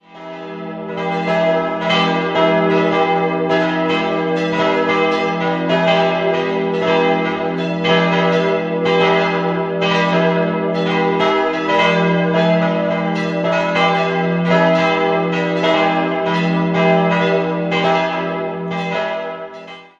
Aus dem Jahr 1894 stammt die Orgel, ein Werk der Firma Kuhn. 4-stimmiges Geläute: e'-gis'-h'-e'' Alle Glocken wurden im Jahr 1840 von Carl Rosenlächer in Konstanz gegossen.